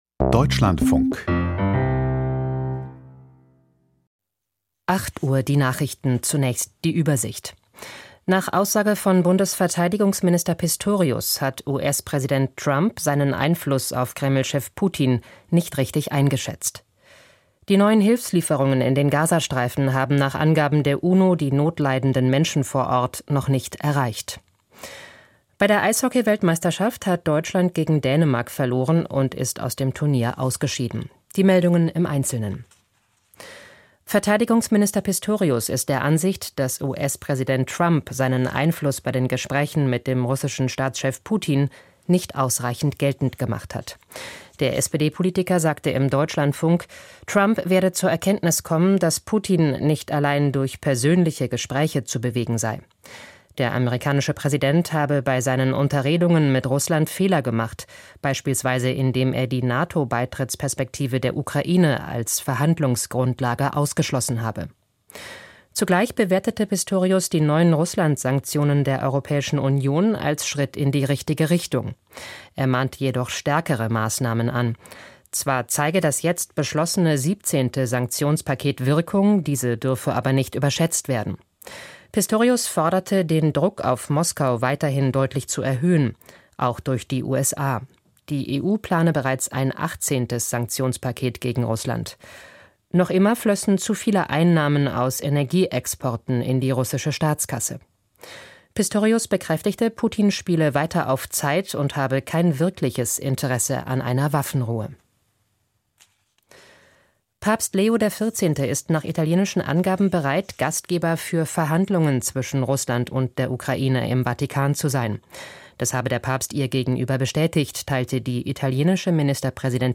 Die Nachrichten vom 21.05.2025, 08:00 Uhr